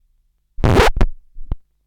Record Scratch #5
comedy crackle effect lp needle noise phonograph record sound effect free sound royalty free Funny